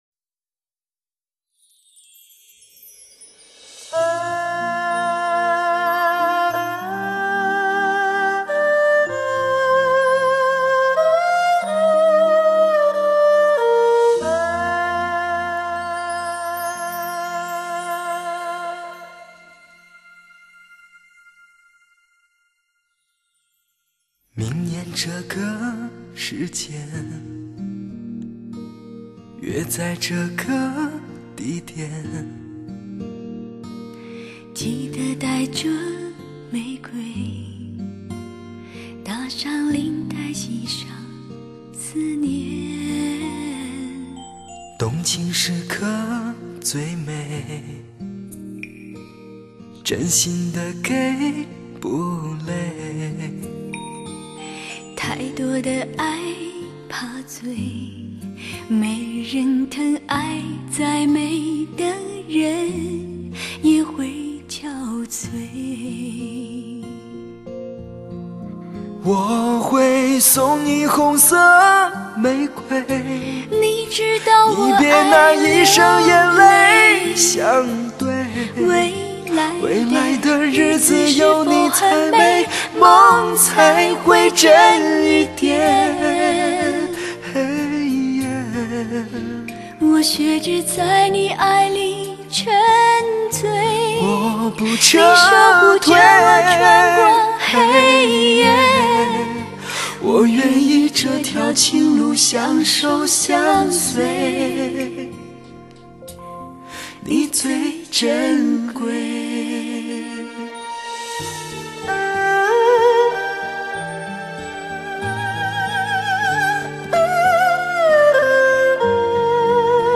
本世纪最深情男声
发烧界最佳女声
强力推荐最佳情歌对唱组合